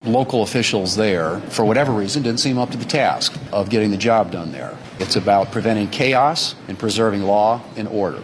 In a press conference on Capitol Hill Tuesday, Thune criticized how local officials have been dealing with the situation.